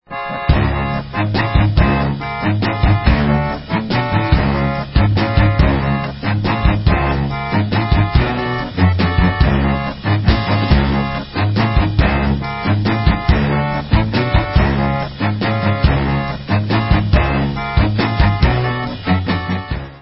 Brass punk from finland